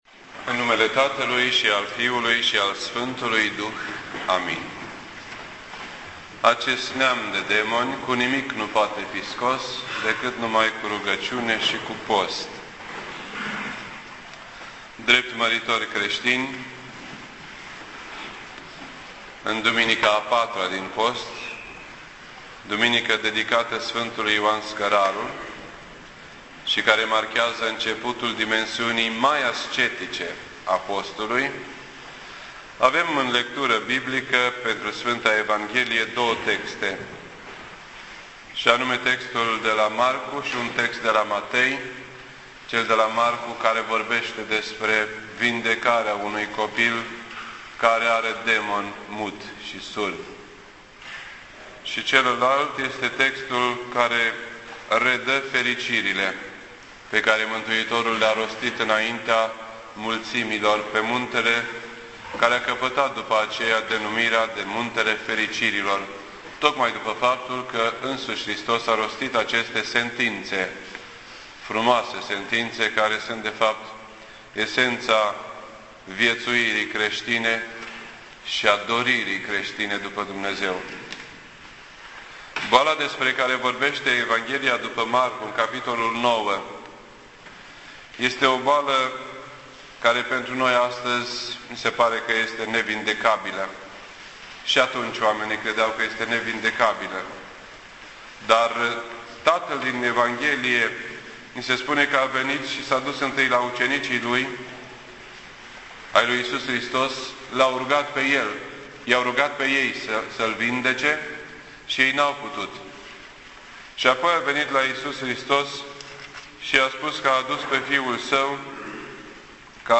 This entry was posted on Sunday, March 14th, 2010 at 8:48 PM and is filed under Predici ortodoxe in format audio.